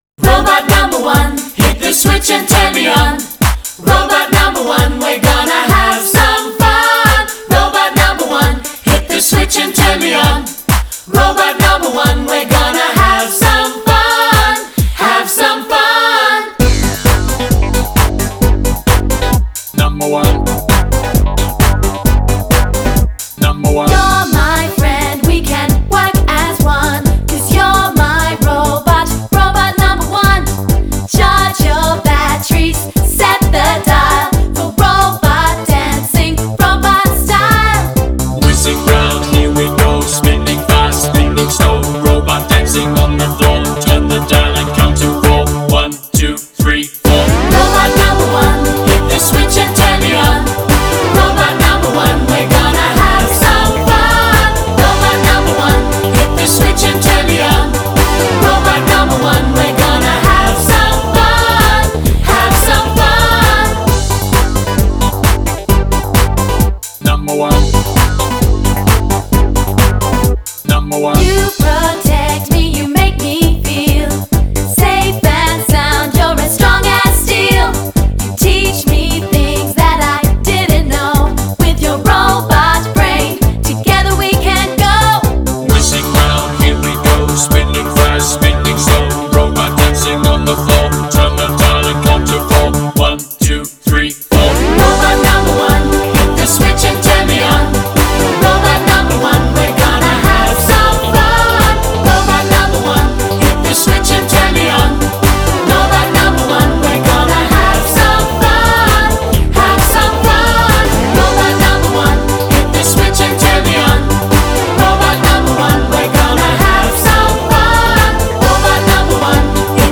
BPM132
Audio QualityPerfect (High Quality)
- Your song must contain both male and female vocals.